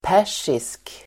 Uttal: [p'är_s:isk]